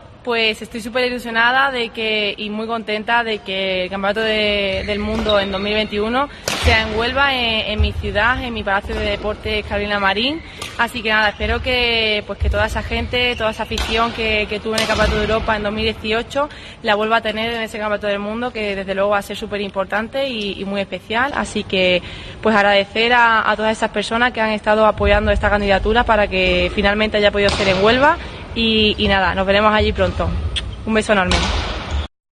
Declaraciones de Carolina a La Liga Sports